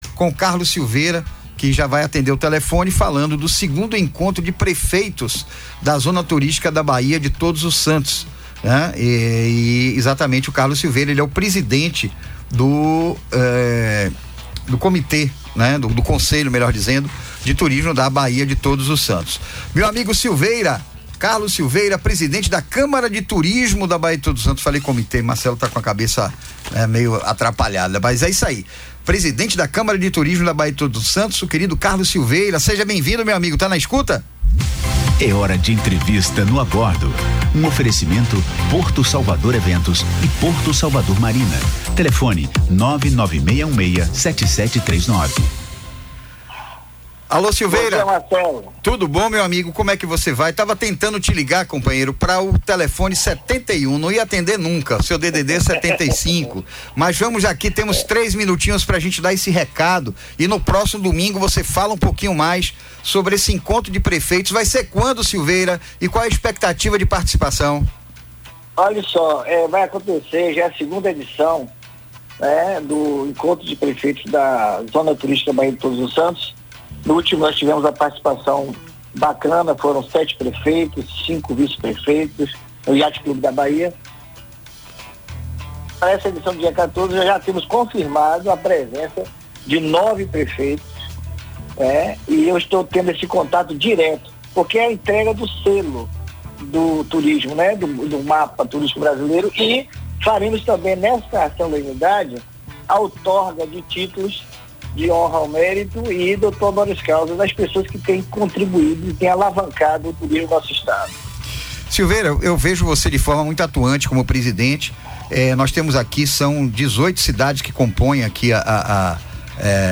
entrevistado nesse domingo